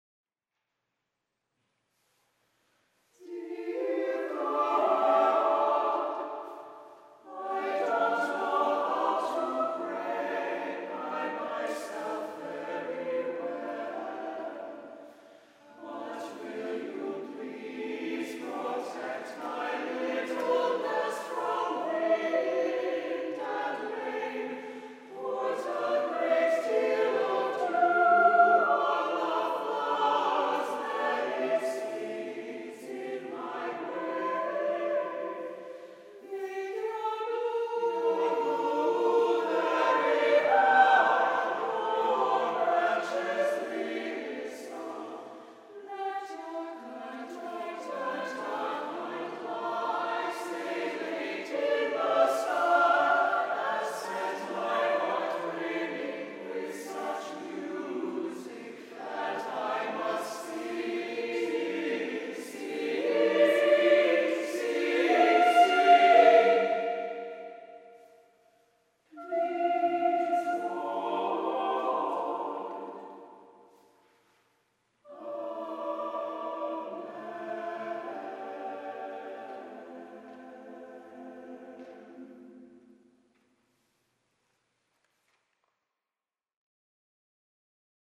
Samples of the choir from this program!